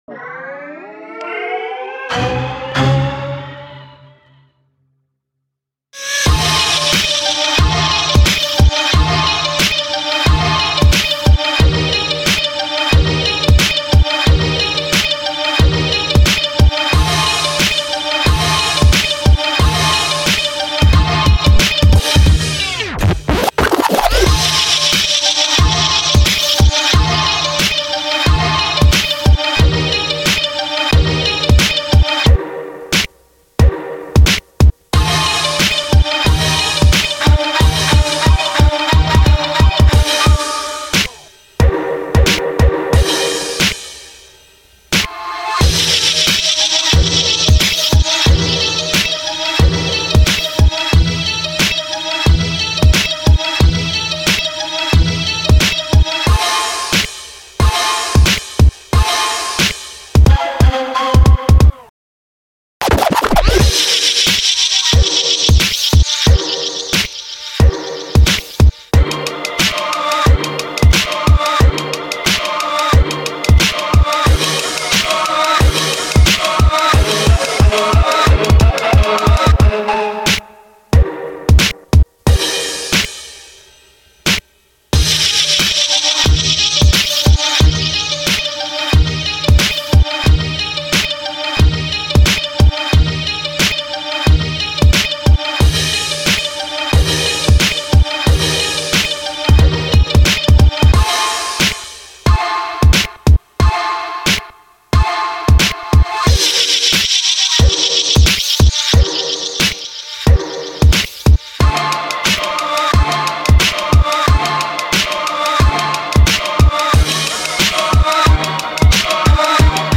ژانر : رپ | هیپ هاپ مود : گنگ گام : Bbm
تمپو : 93